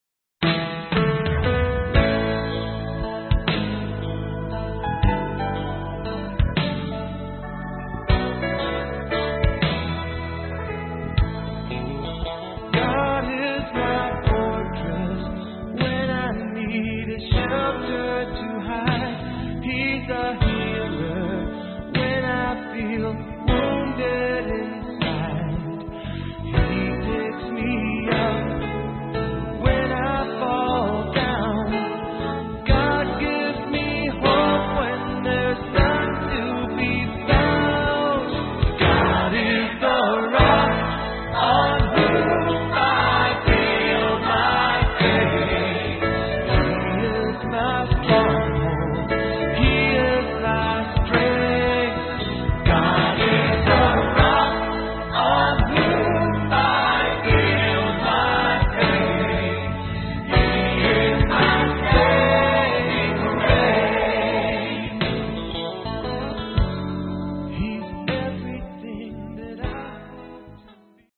Style: Contemporary Christian